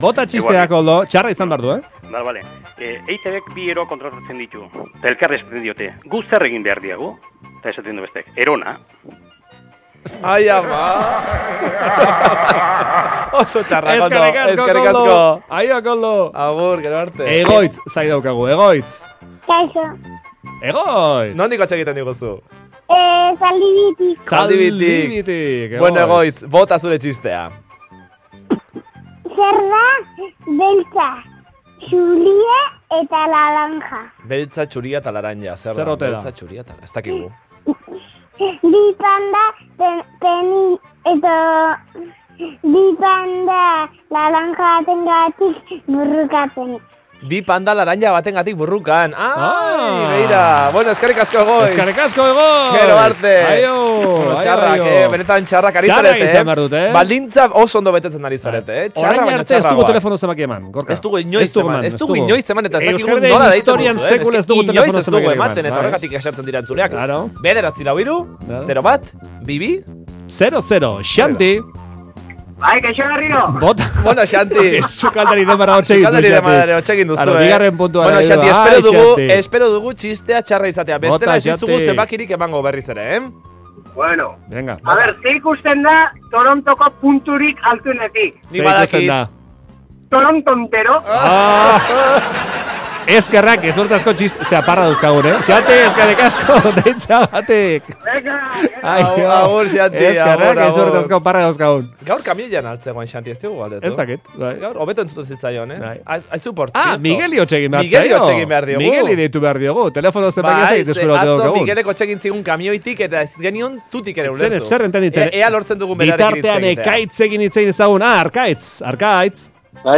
Gero eta barregarriagoak, etzuleek kontatzen dizkiguten txisteak.